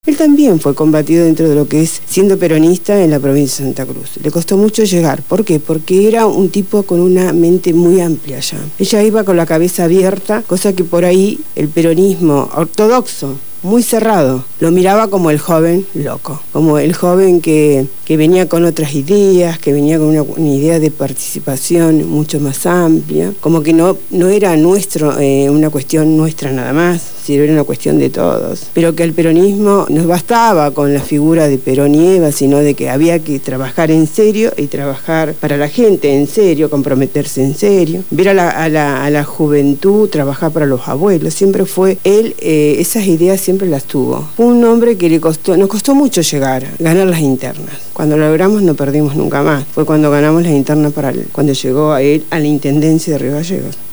Estuvieron en los estudios de Radio Gráfica FM 89.3 durante el programa «Punto de partida» y nos conducieron por un relato desde las primeras internas que culminan llevándolo a la Intendencia de Río Gallegos, las anécdotas, las dificultades en la función de gobierno, sus sueños y las horas interminables de trabajo desde la visión de dos militantes de la primera hora.